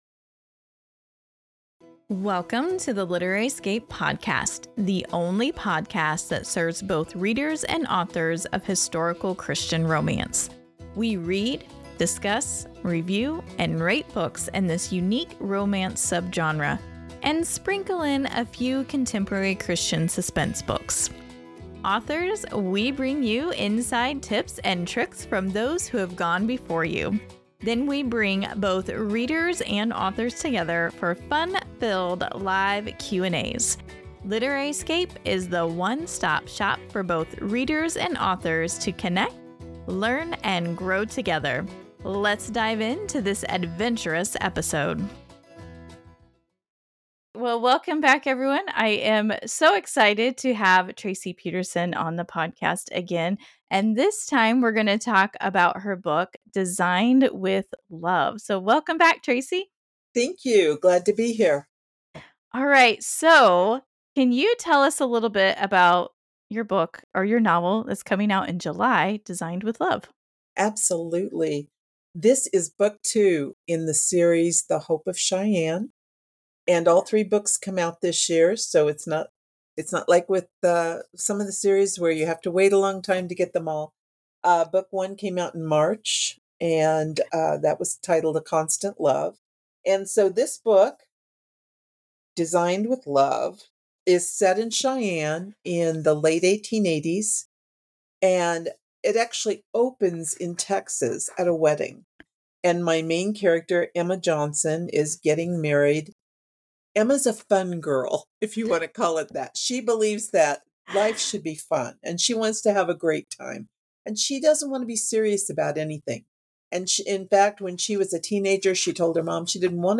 Designed+with+Love+Interview_mixdown.mp3